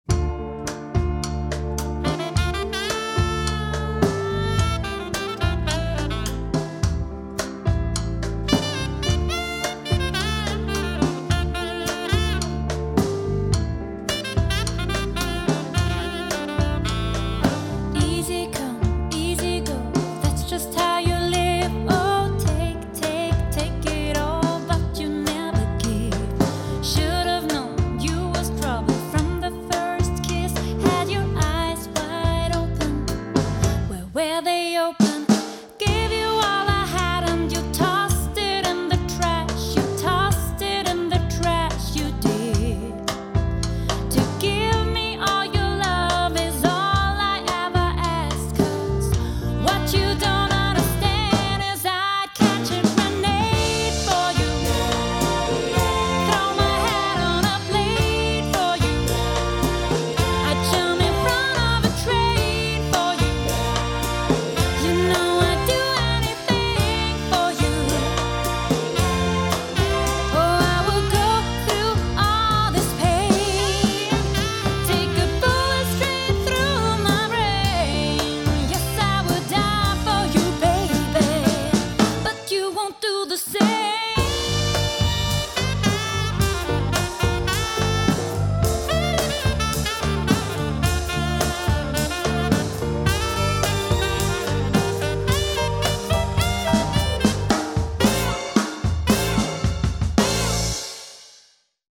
Quartett bis Sextett